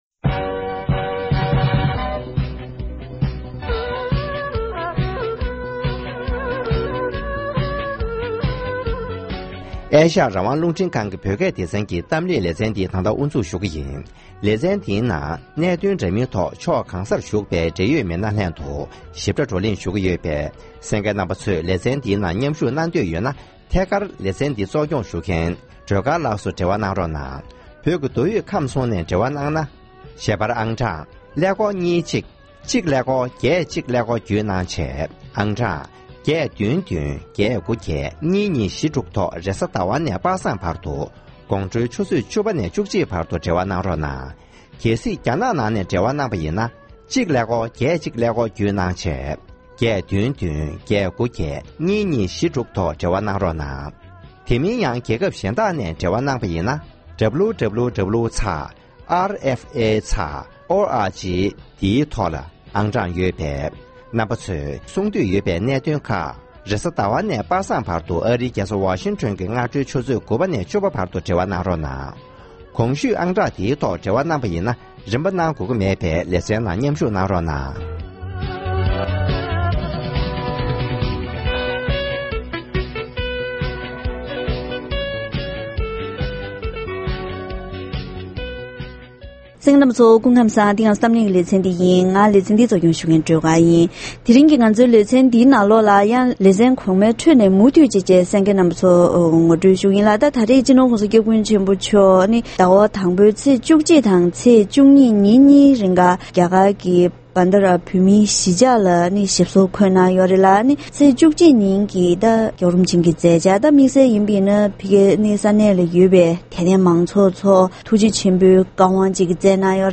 ནོར་རྒྱས་གླིང་བོད་མིར་བཀའ་སློབ་སྩལ་བ།
སྤྱི་ནོར་༸གོང་ས་༸སྐྱབས་མགོན་ཆེན་པོ་མཆོག་ནས་བྷན་ངྷ་ར་ནོར་རྒྱས་གླིང་གི་བོད་མི་རྣམས་ལ་དམིགས་བསལ་བཀའ་སློབ་སྩལ་བ།